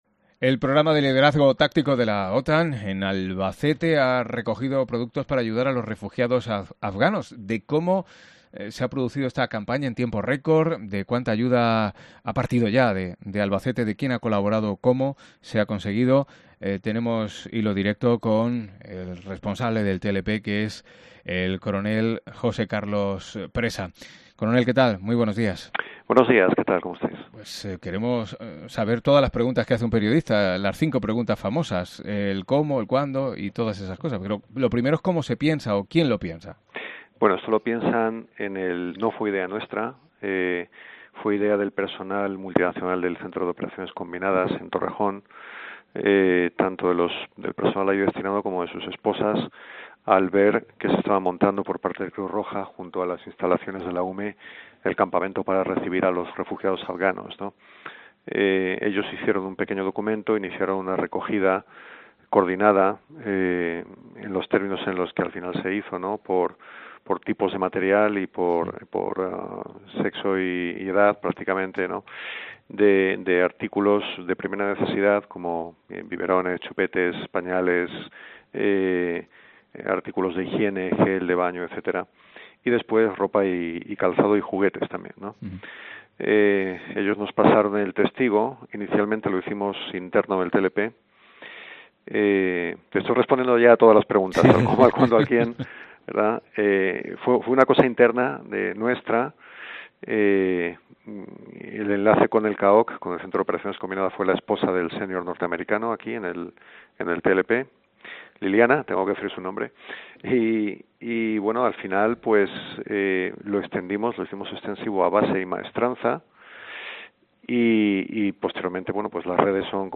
ENTREVISTA COPE